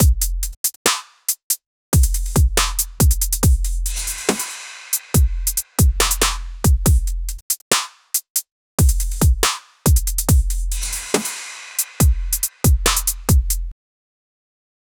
a-punchy-and-crisp-75-w7pwmlwr.wav